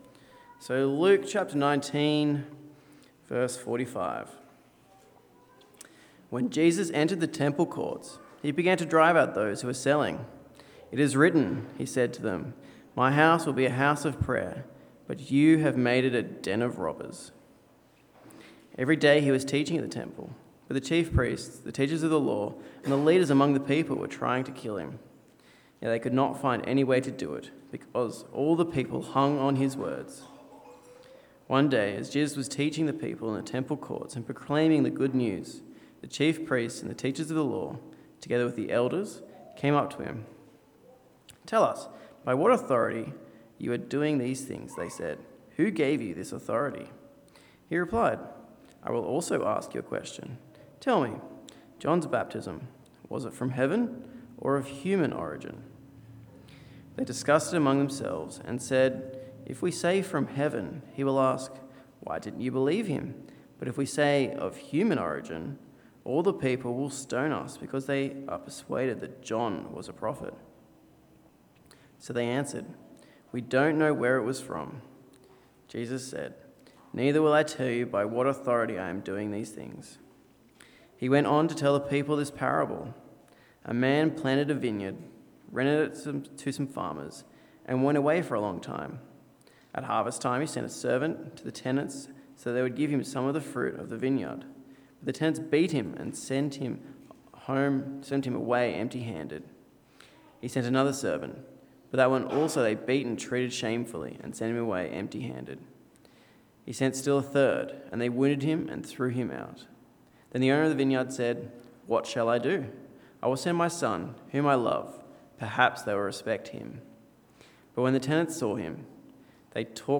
Service Type: Rosemeadow AM